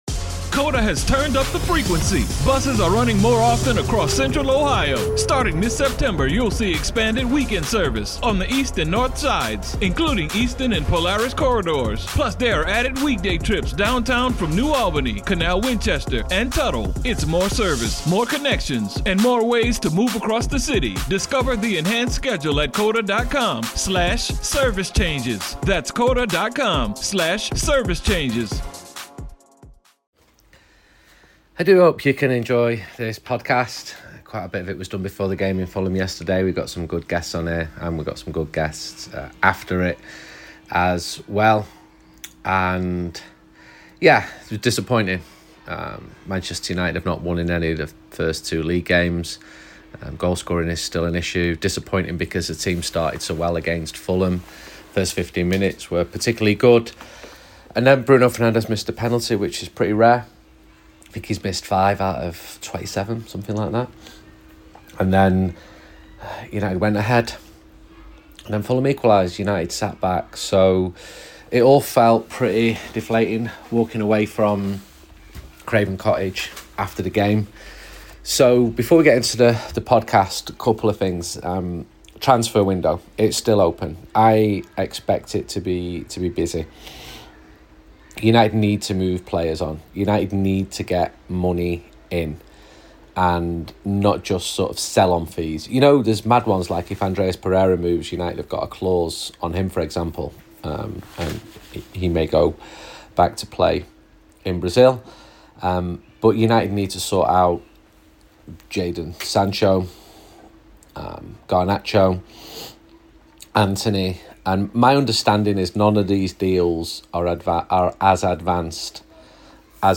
in West London with fans, journalists and comedians.